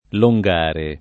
[ lo jg# re ]